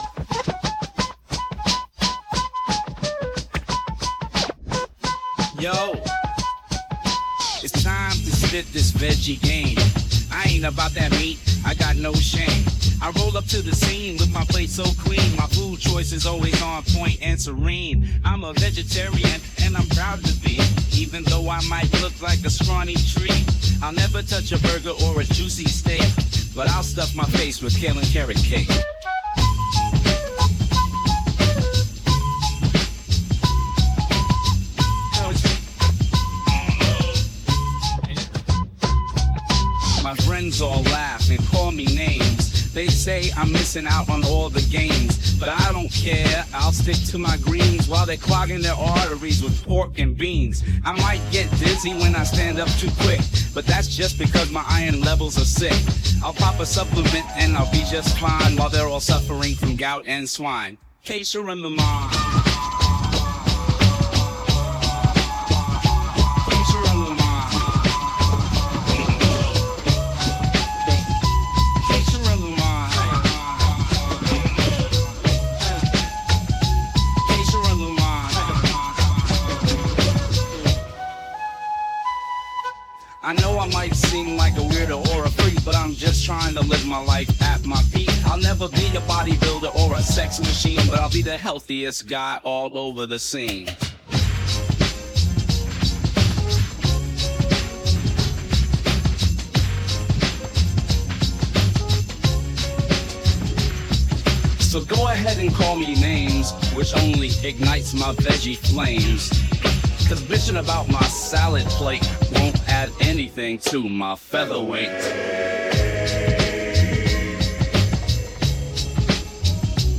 Finally, I made some minor tweaks to the music (mainly responsible for the little imperfections you might hear) with the help of audacity.
Version 1: Music by Udio (V1)